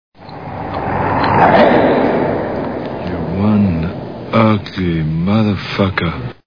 Predator Movie Sound Bites